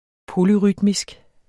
Udtale [ ˈpolyˌʁydmisg ]